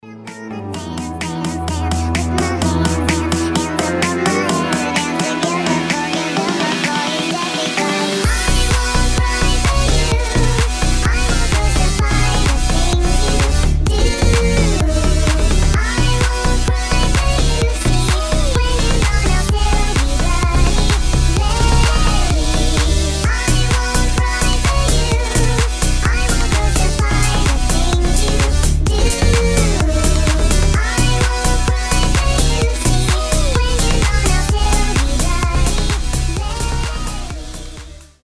• Качество: 320, Stereo
Electronic
EDM
ремиксы